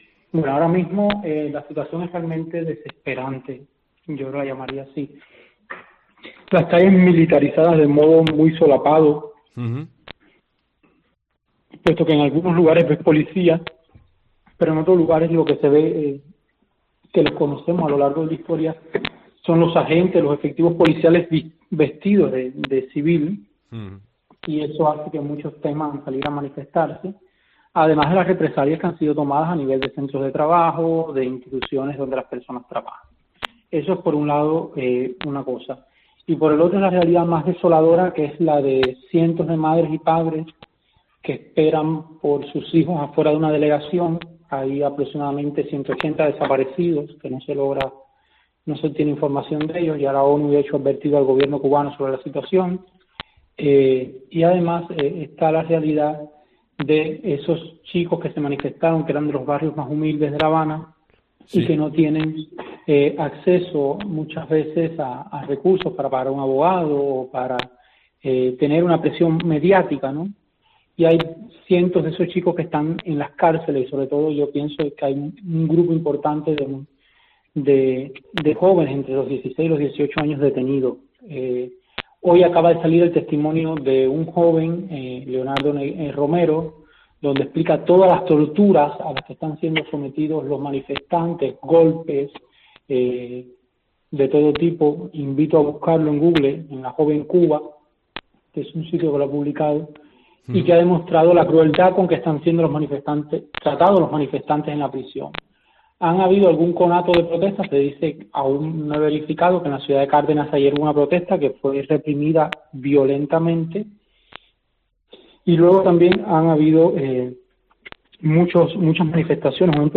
Desde COPE, hemos podido hablar con un ciudadano de la capital cubana.